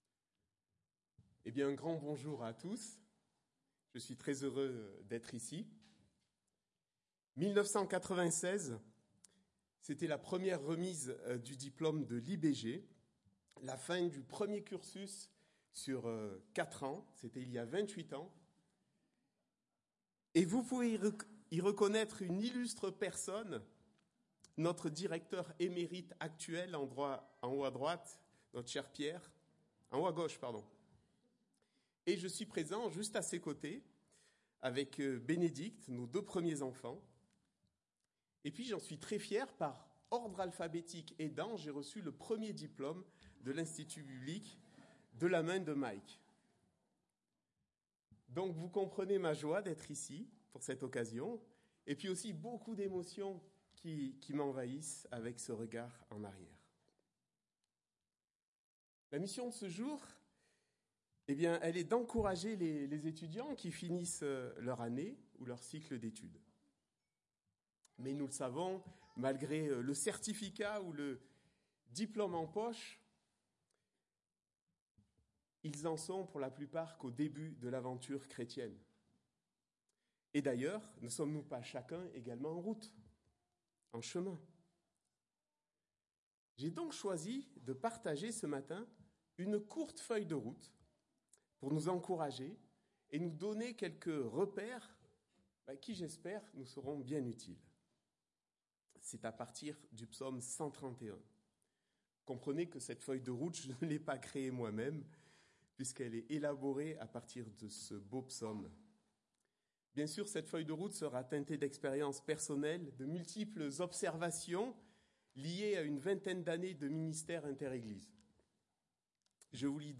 Message culte de clôture 2024